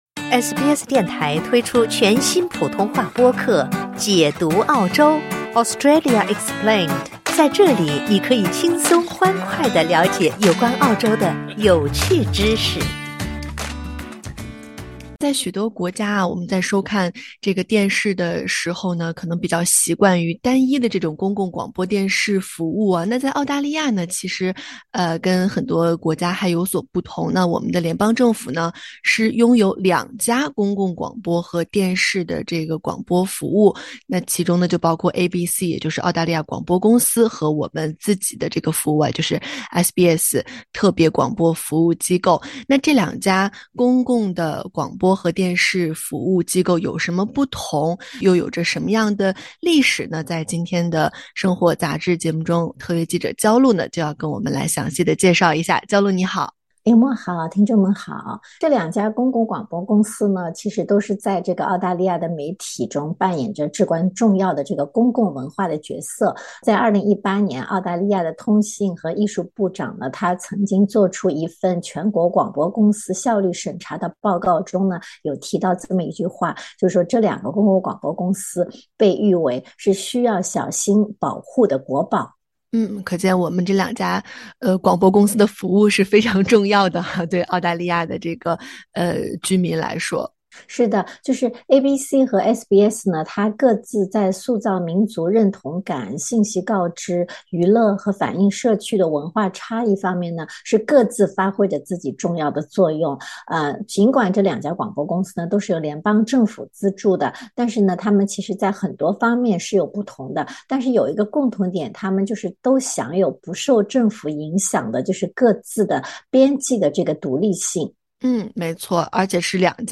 这两家公共广播公司提供哪些广播和电视服务？他们可以信任吗？还有谁是其他的竞争对手？政府是否可以对两家公司进行干预？ 欢迎您点击音频，收听完整采访。